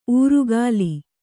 ♪ ūrugāli